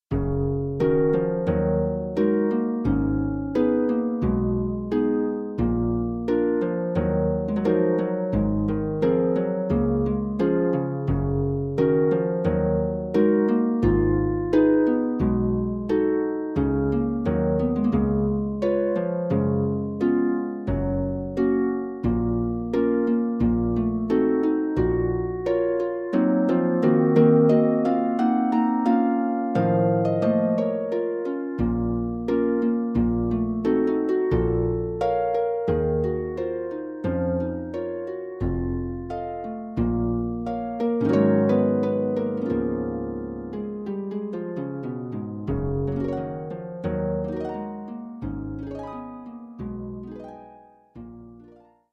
for solo pedal harp